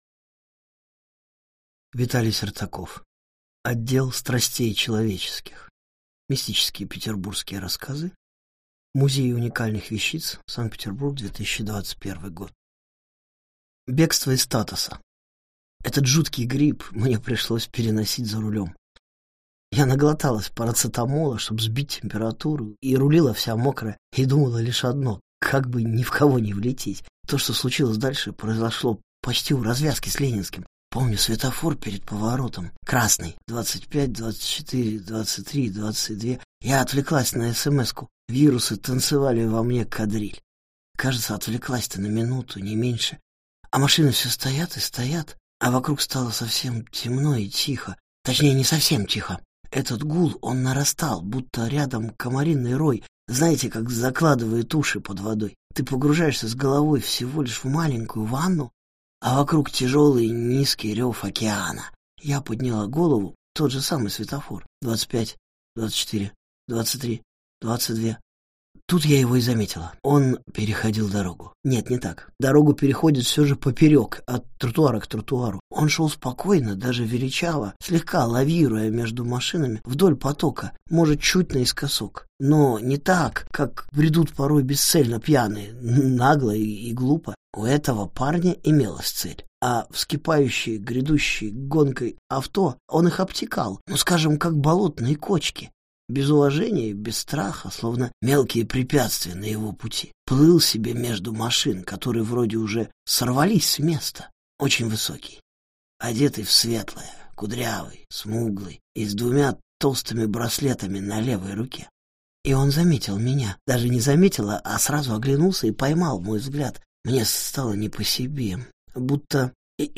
Аудиокнига Отдел страстей человеческих | Библиотека аудиокниг